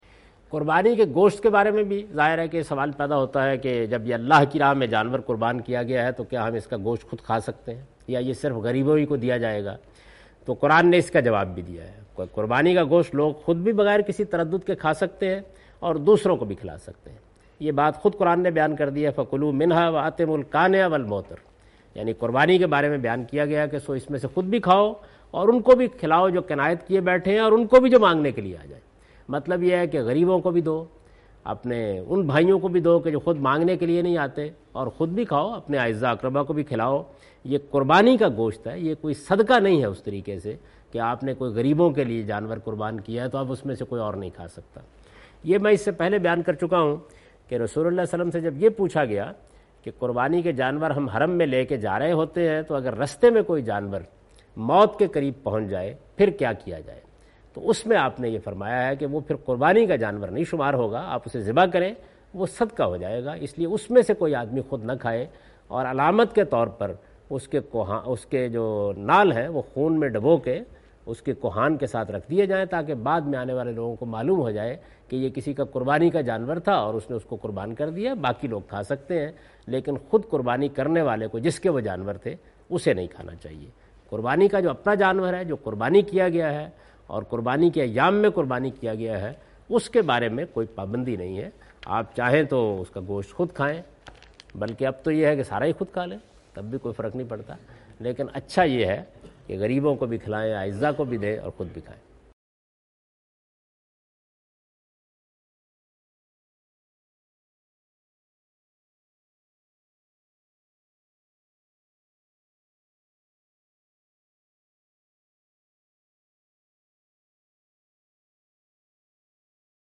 In this video of Hajj and Umrah, Javed Ahmed Ghamdi is talking about "Eating Meat of Sacrifice Animals".
حج و عمرہ کی اس ویڈیو میں جناب جاوید احمد صاحب غامدی "قربانی کے جانور کا گوشت" سے متعلق گفتگو کر رہے ہیں۔